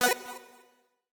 Universal UI SFX / Clicks
UIClick_Retro Delay 05.wav